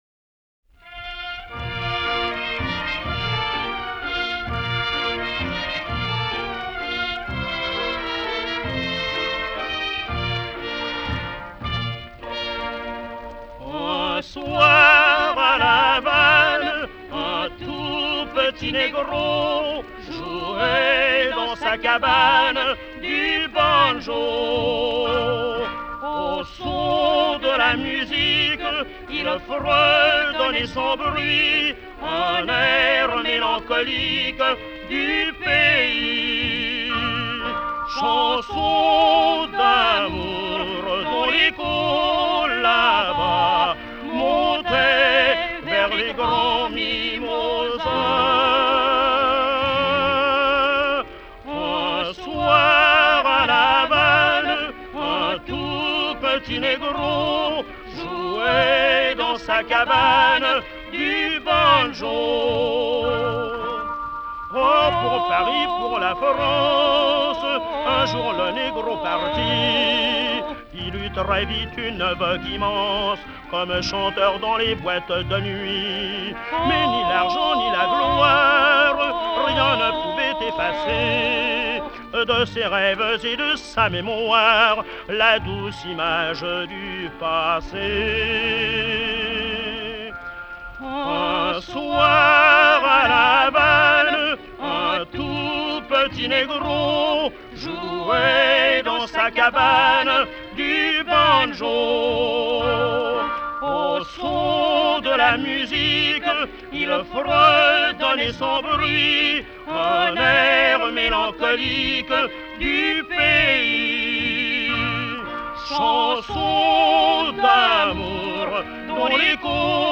Sur un fond de « valse hawaïenne »